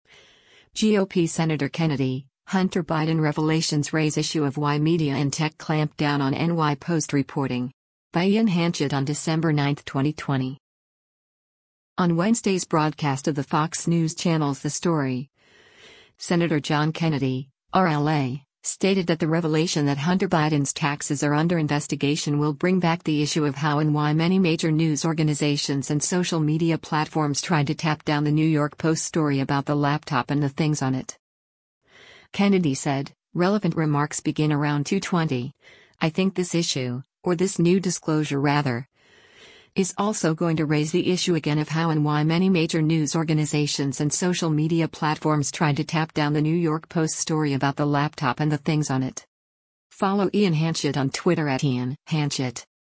On Wednesday’s broadcast of the Fox News Channel’s “The Story,” Sen. John Kennedy (R-LA) stated that the revelation that Hunter Biden’s taxes are under investigation will bring back the issue “of how and why many major news organizations and social media platforms tried to tap down the New York Post story about the laptop and the things on it.”